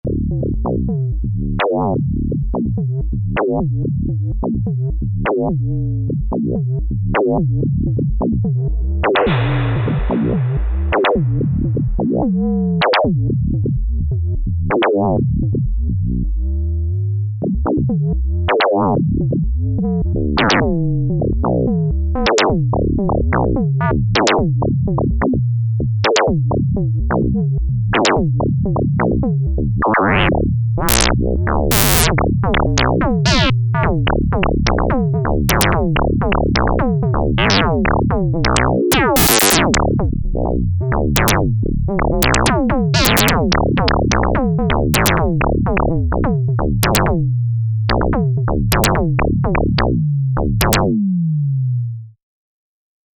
Instead use Filter 2 as a sine wave (res fully cranked) and use the LFOs and Filt Env to control the filter freq.